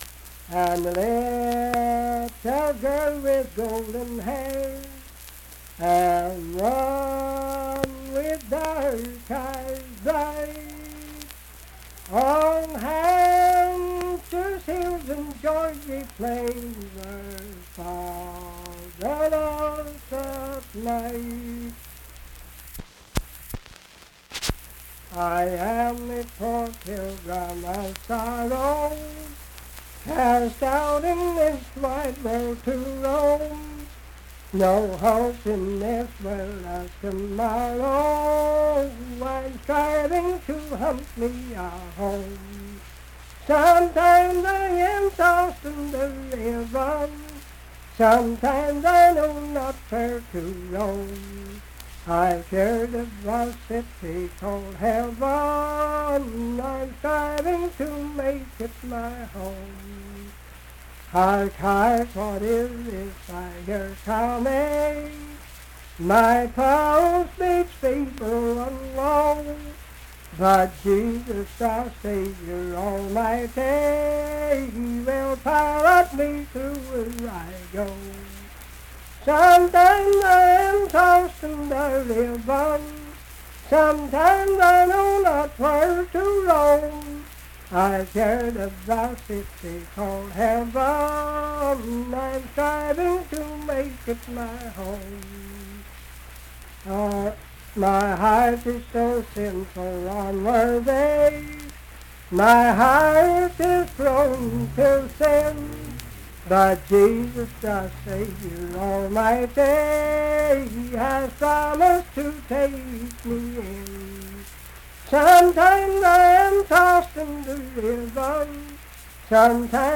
Unaccompanied vocal music
Performed in Ivydale, Clay County, WV.
Hymns and Spiritual Music
Voice (sung)